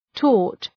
Προφορά
{tɔ:rt}